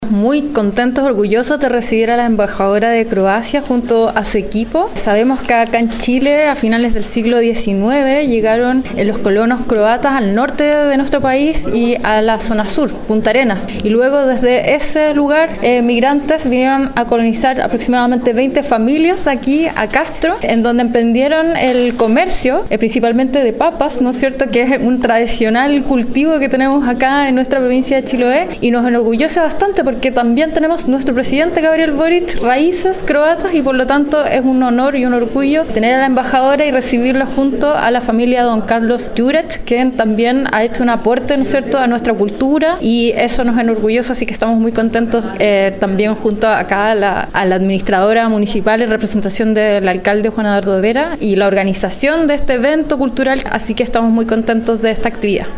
En tanto, la Delegada Provincial, Mariela Núñez, se mostró muy contenta y orgullosa de recibir a la embajadora croata en Chiloé: